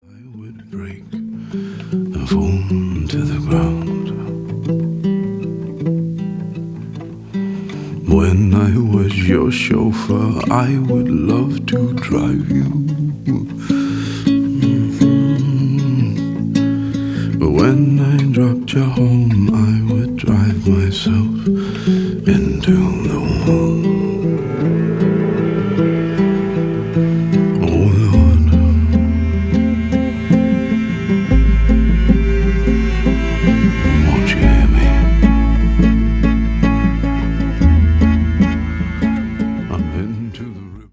• Grunge
• Jazz
• Singer/songwriter
Poesi og mystic og ro og varme.